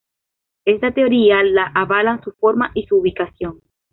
te‧o‧rí‧a
Pronúnciase como (IPA)
/teoˈɾia/